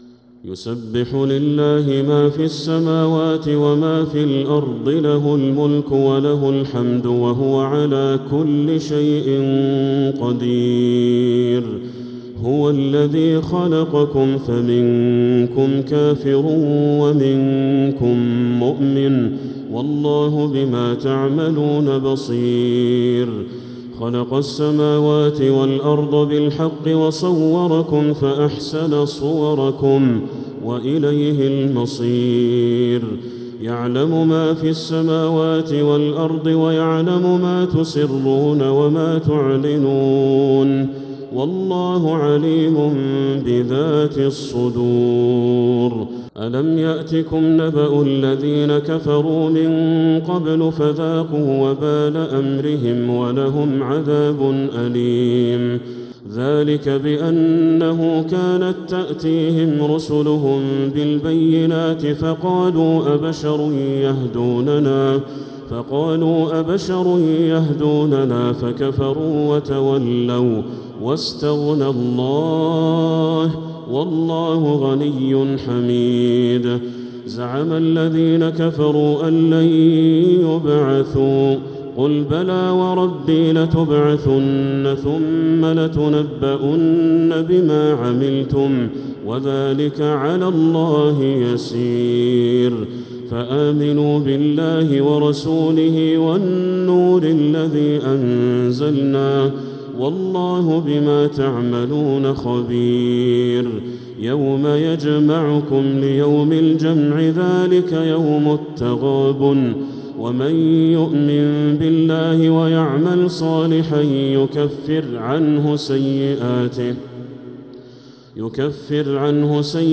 سورة التغابن كاملة | رمضان 1446هـ > السور المكتملة للشيخ بدر التركي من الحرم المكي 🕋 > السور المكتملة 🕋 > المزيد - تلاوات الحرمين